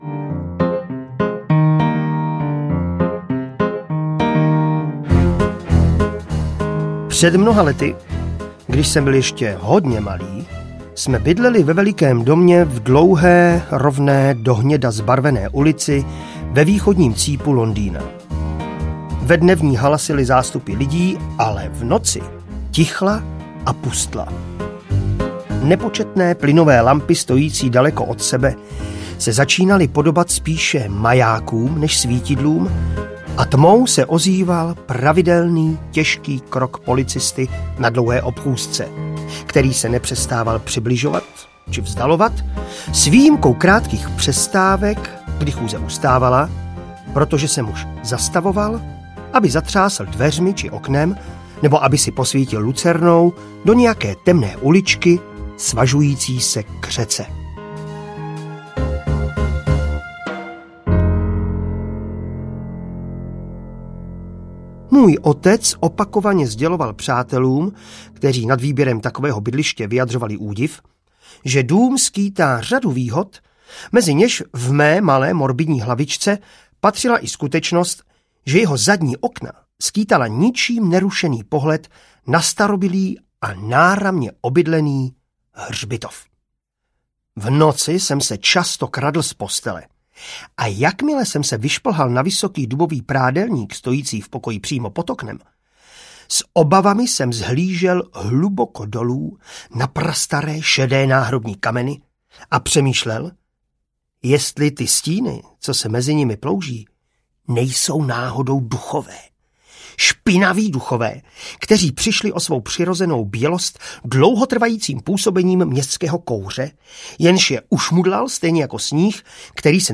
Audio kniha
Ukázka z knihy
• InterpretMartin Dejdar